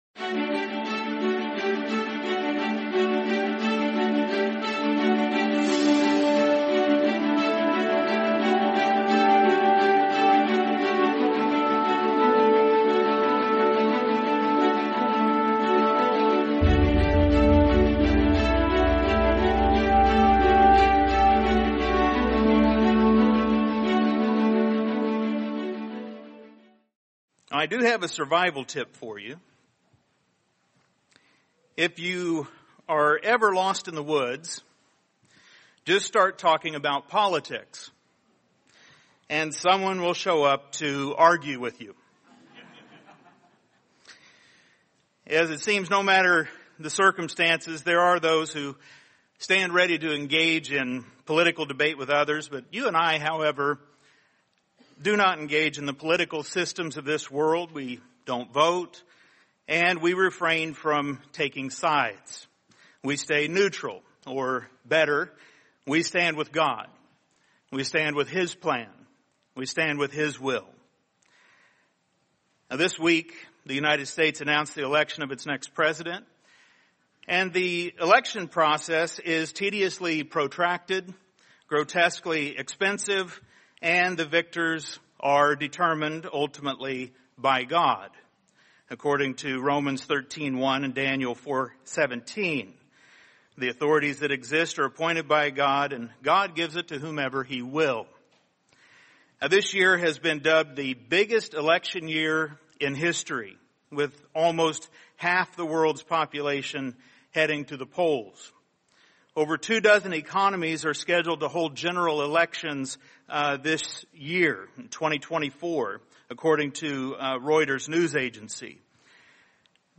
Making Our Calling and Election Sure | Sermon | LCG Members